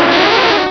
pokeemerald / sound / direct_sound_samples / cries / shellder.aif
-Replaced the Gen. 1 to 3 cries with BW2 rips.